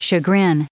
1336_chagrin.ogg